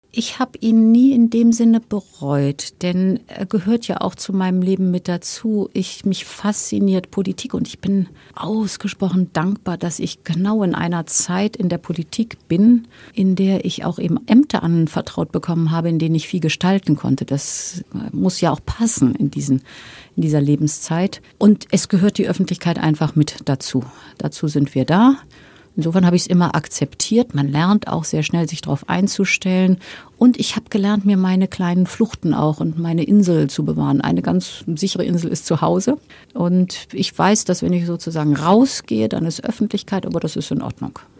WhatsApp im Kabinett? Was kommt Weihnachten auf den Tisch? Wie funktioniert Abschalten von der Arbeit am besten? Bundesverteidigungsministerin Ursula von der Leyen war zu Gast in unserer Einsatzredaktion in Afghanistan und gewährte dabei tiefe Einblicke in ihr Privatleben.